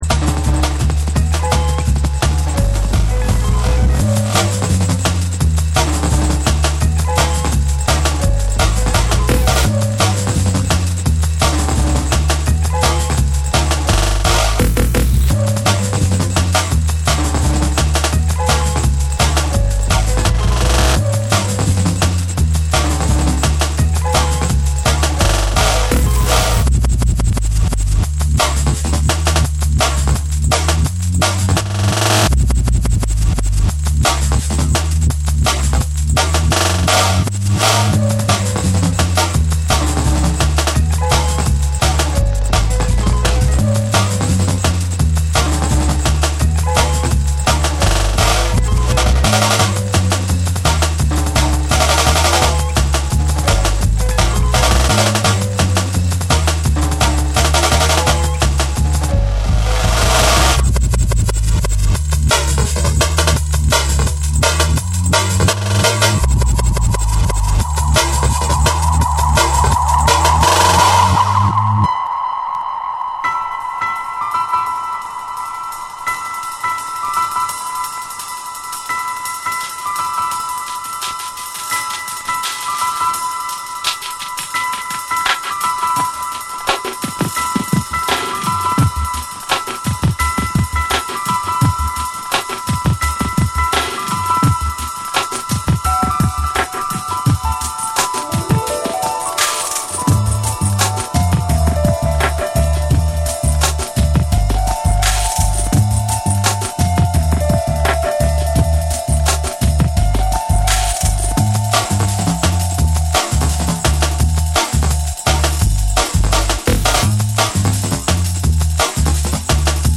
底の方から叩き打つビートにグルーヴィーで深みのあるベースライン、ジャジーなサックスが一体となる
BREAKBEATS / JUNGLE & DRUM'N BASS